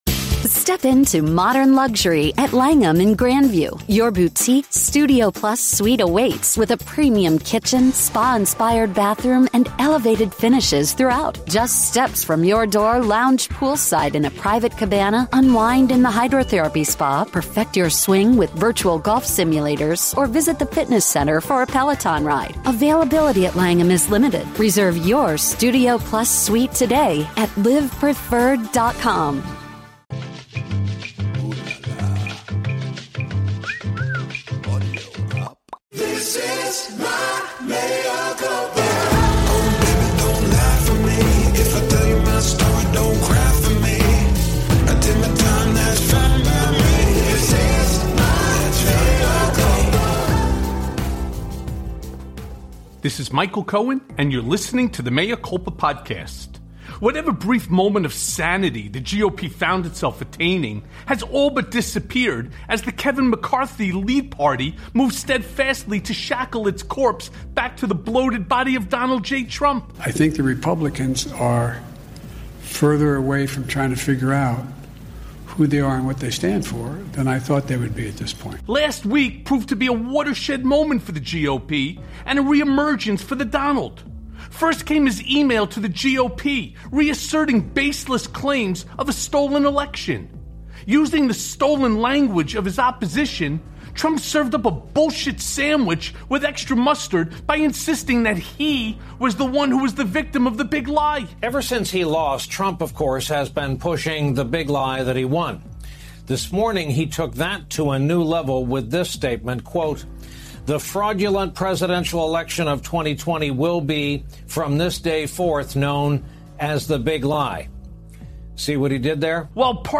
Breaking!!! Are the Feds Ready to Flip Rudy? + A Conversation with MSNBC’S Jonathan Capehart